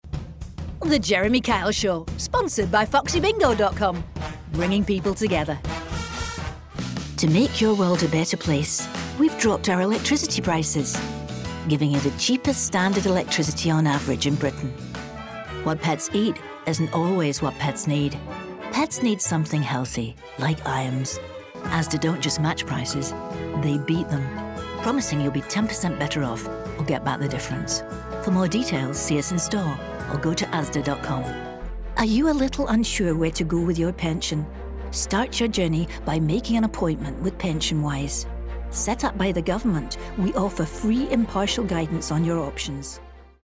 Commercial Accent Reel
Accent, Commercial, Showreel